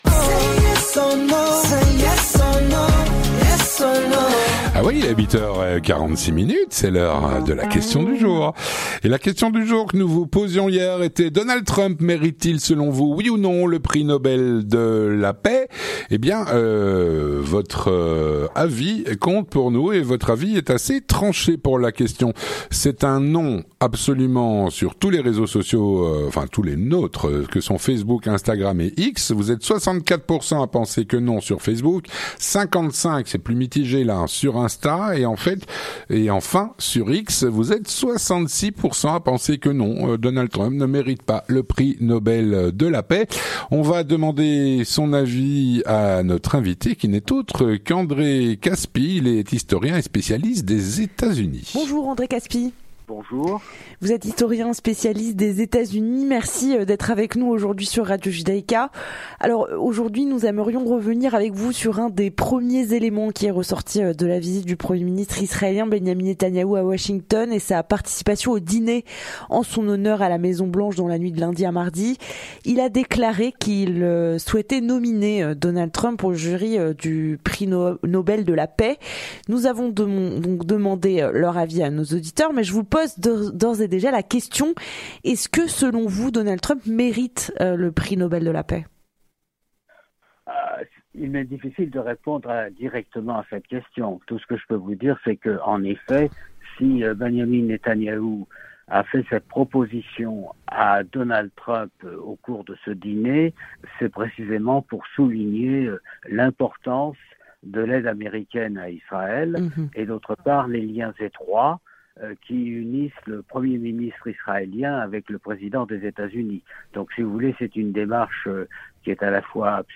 André Kaspi, historien spécialiste des Etats-Unis, répond à "La Question Du Jour".